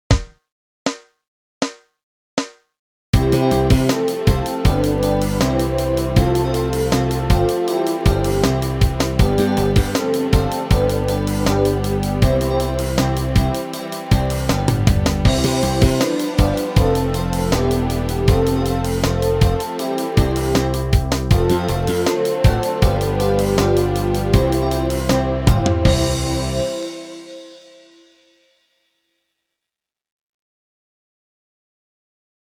Obsazení: Flöte